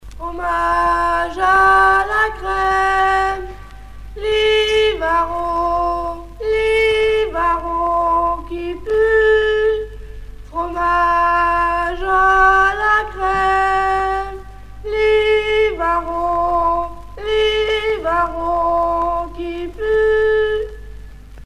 Cris de rue d'une marchande de Livarot
gestuel : à interpeller, appeler
Genre brève